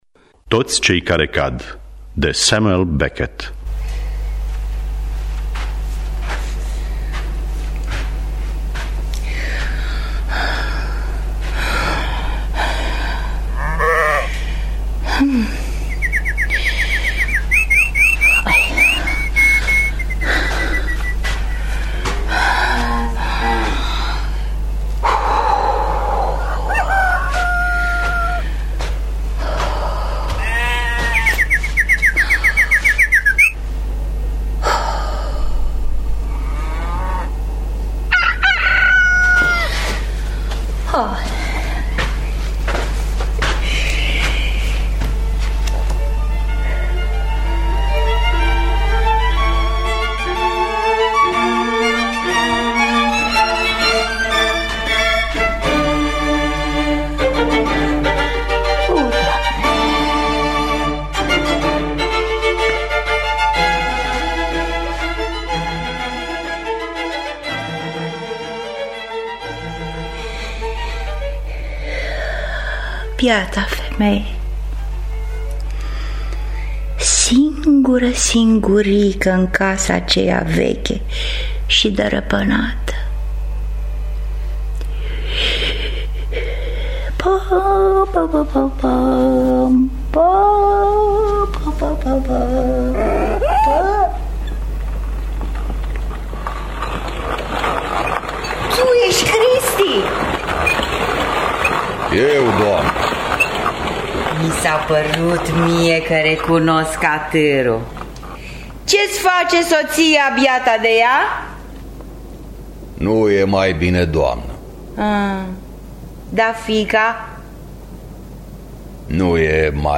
Toți cei care cad de Samuel Beckett – Teatru Radiofonic Online